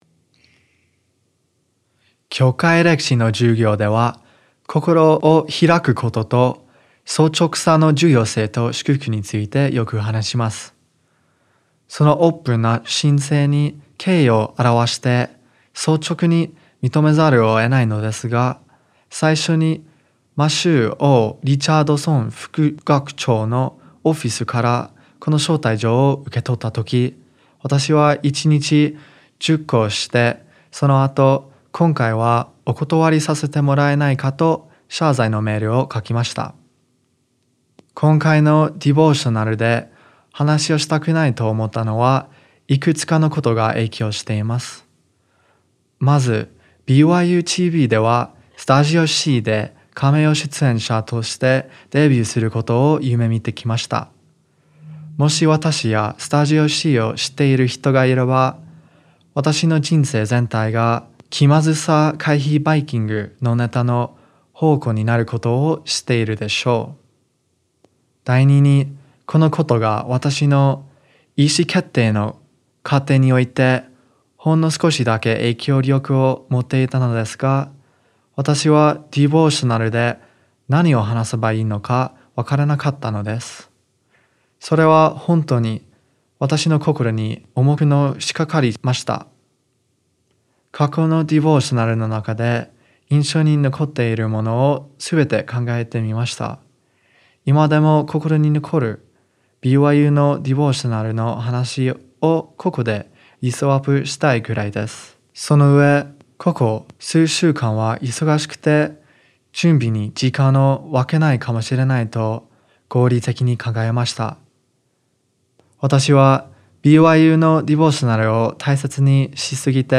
ディボーショナル